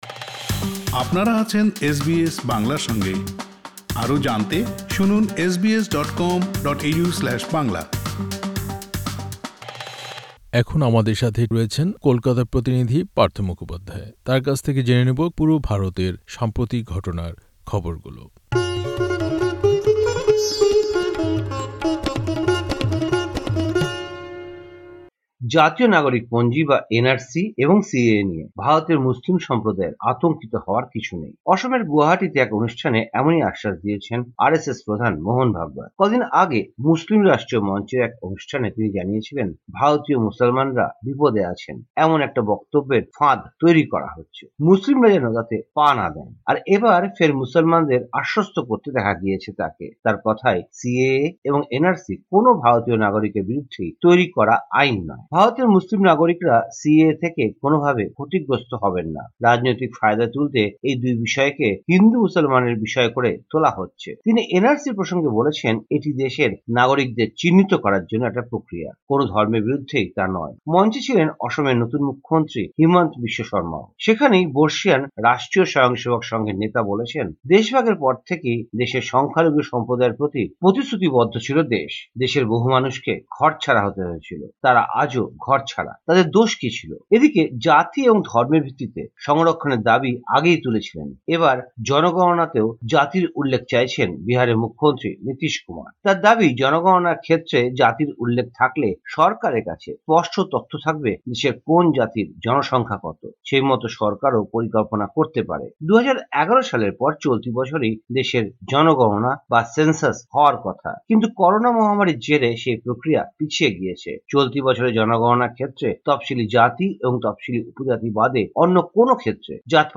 ভারতীয় সংবাদ: ২৬ জুলাই ২০২১
কলকাতা থেকে